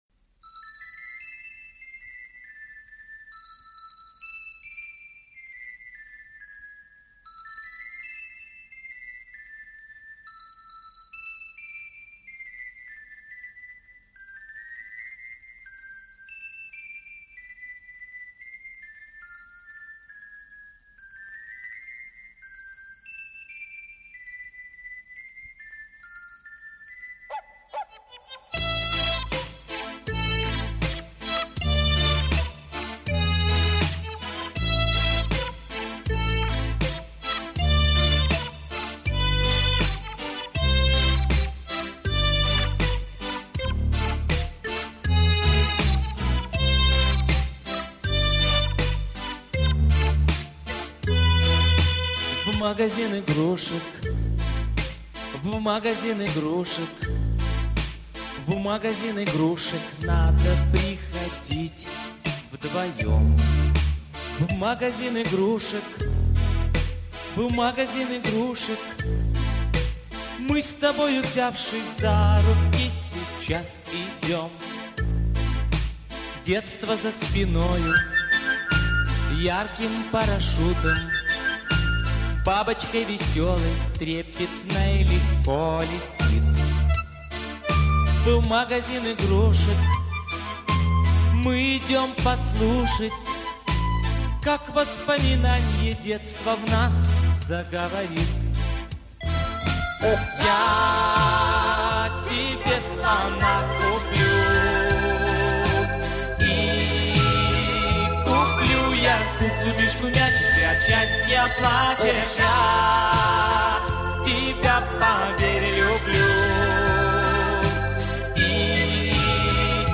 Где –то есть концертная запись.
гитара.